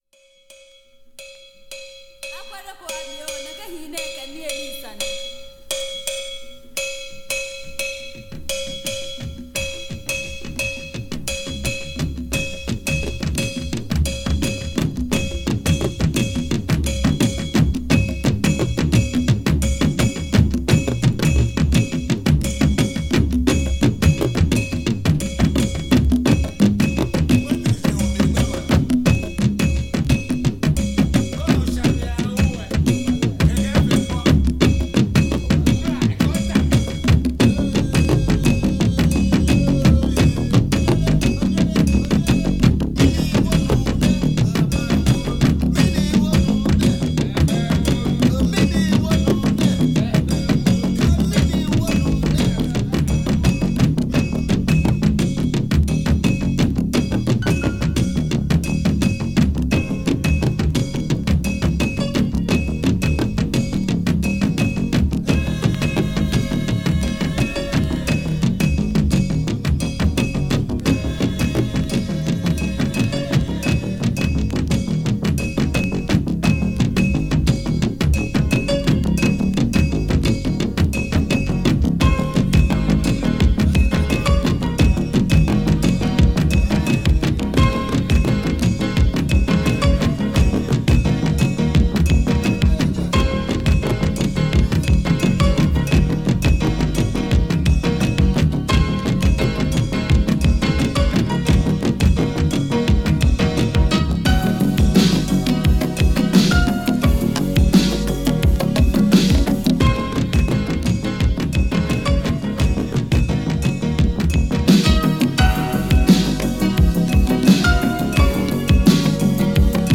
ELECTRONIC
鍵盤奏者